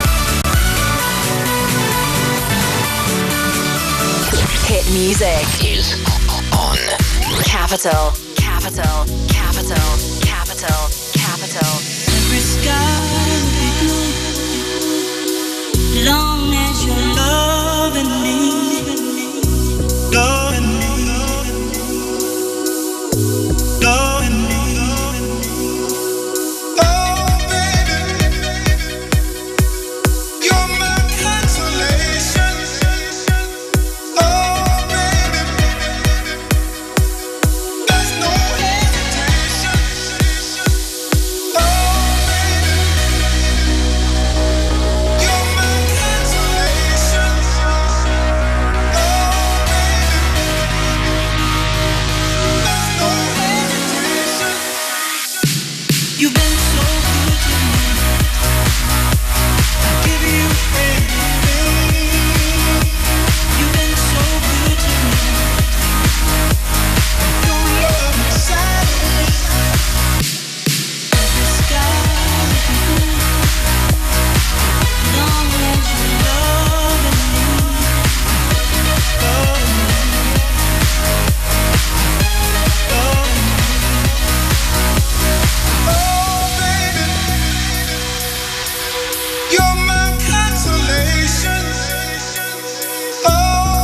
1 – Questa registrazione è stata effettuata a Londra il 27 Luglio 2013 da Capital Fm, Bitrate 128 Kbs a 48 Khz in formato MPG2 o MP2 quindi in DAB, secondo me molto scarso e pieno di artefatti.